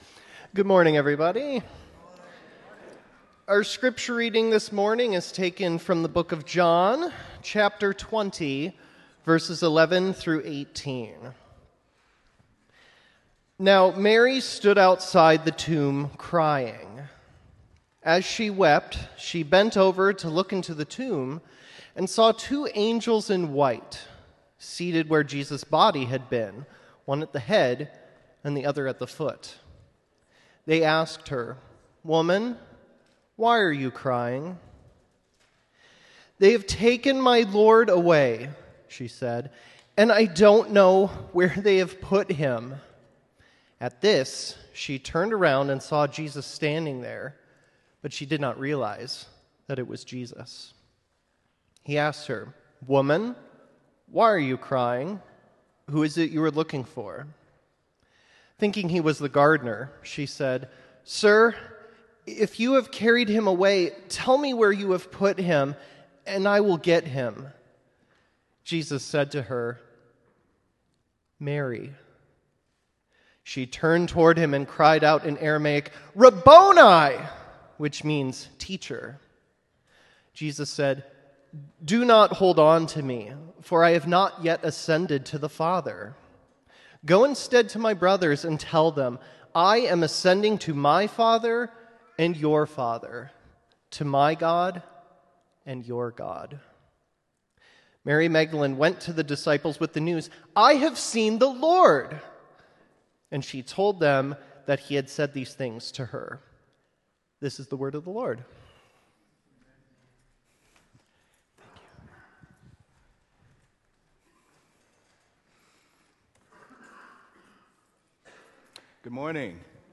sermon-easter-2025-i-have-seen-the-lord.m4a